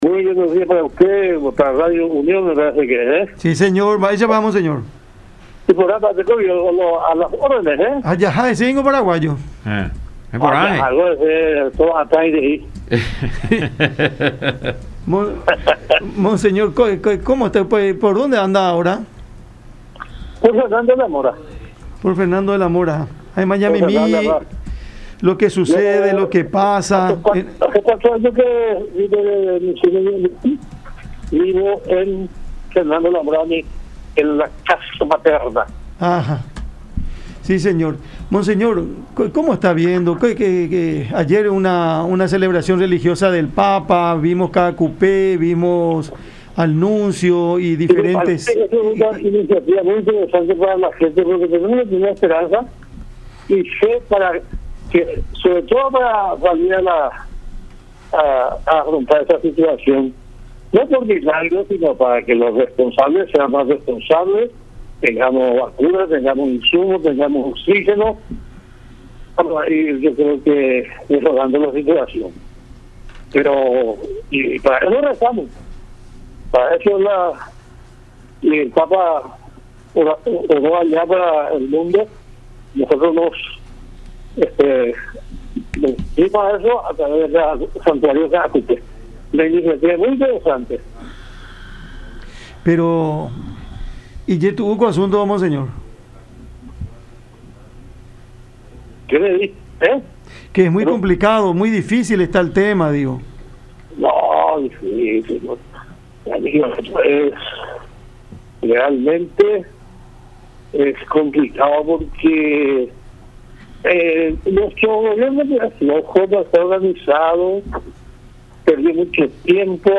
Tenemos un Estado fallido lamentablemente”, dijo Medina en conversación con Cada Mañana por La Unión.